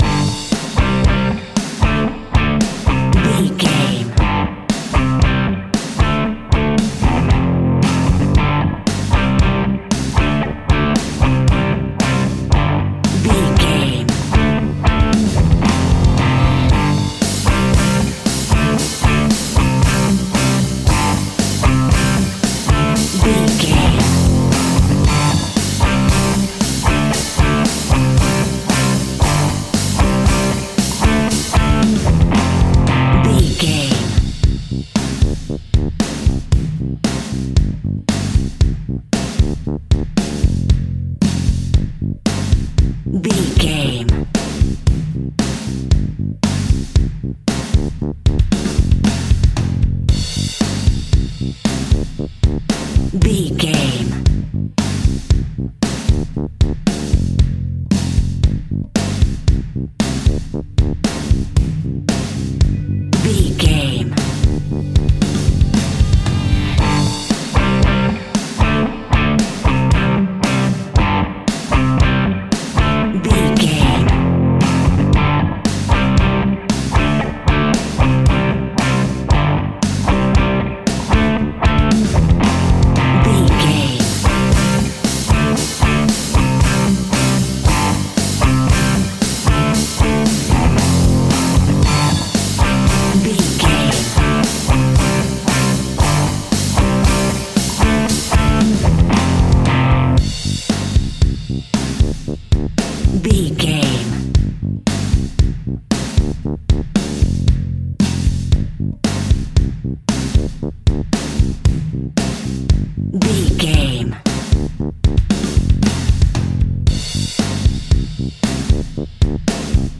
Epic / Action
Ionian/Major
hard rock
heavy metal
blues rock
Rock Bass
heavy drums
distorted guitars
hammond organ